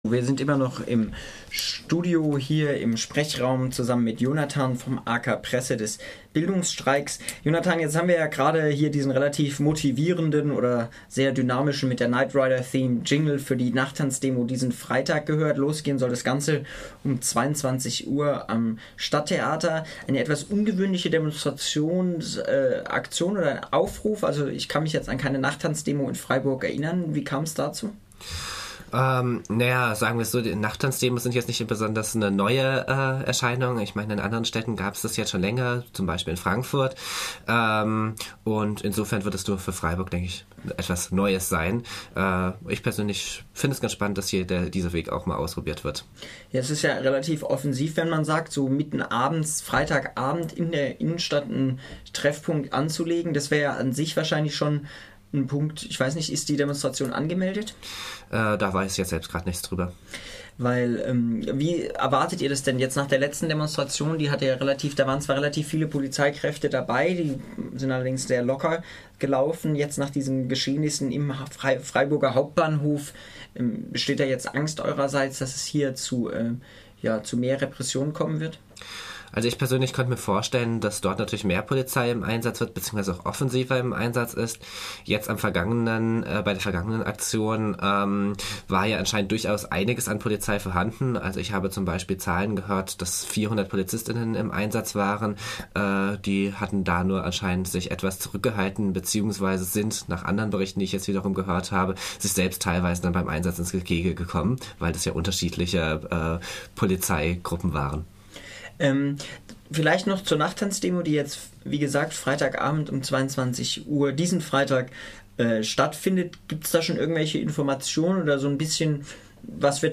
Interview mit einem Vertreter des "AK Presse" des Bildungstreiks zur Demo vom 09. Juni und